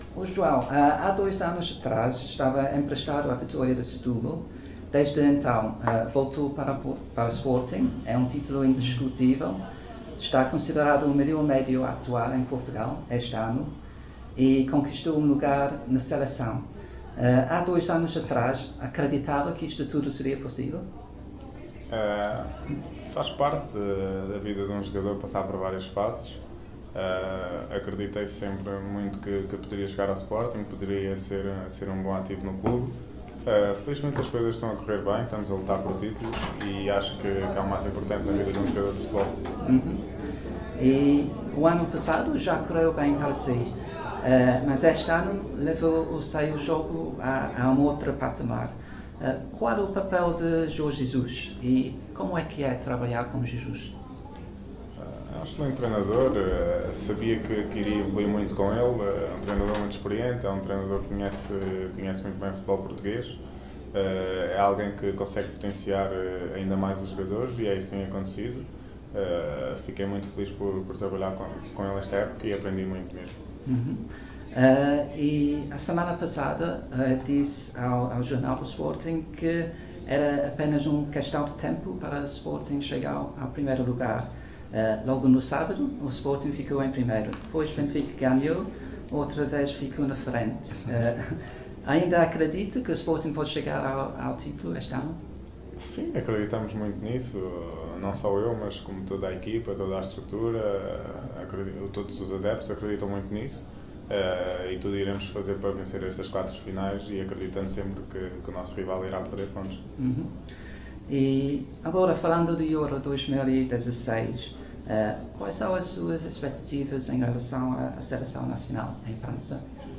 João Mário interview (20/04/2016)